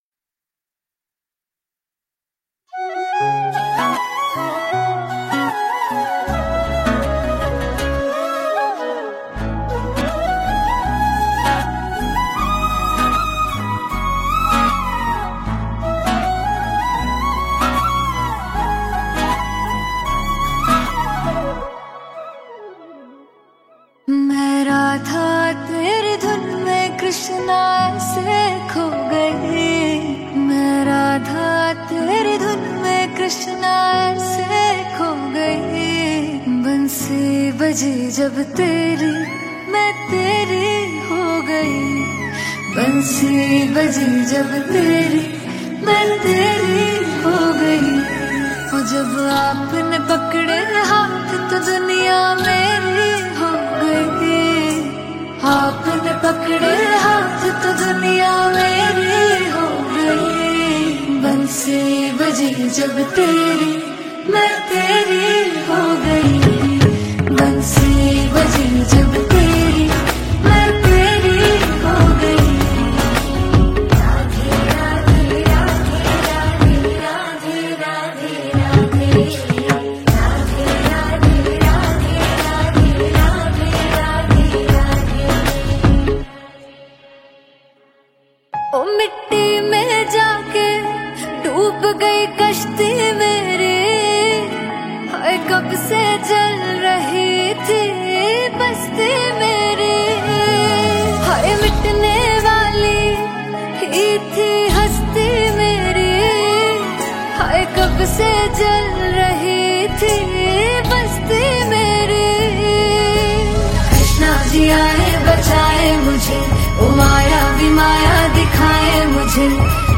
Bhakti Songs » Krishna Song